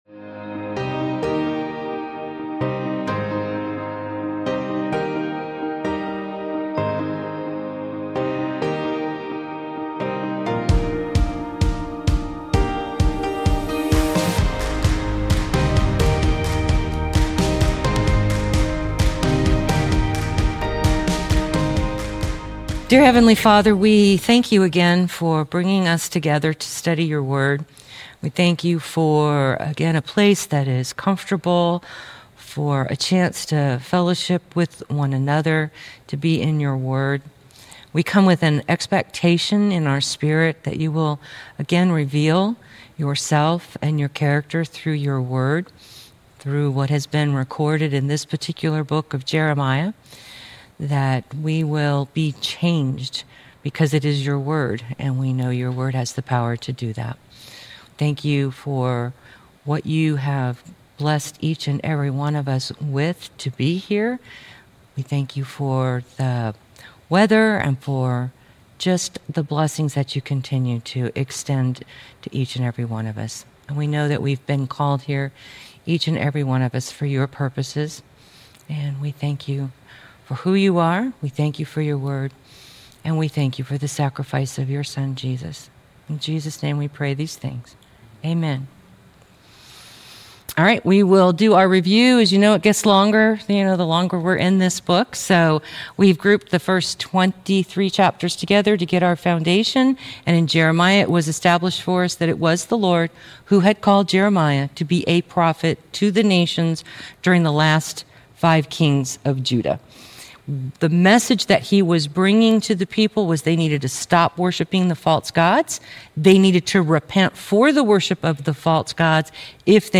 Jeremiah - Lesson 42-43 | Verse By Verse Ministry International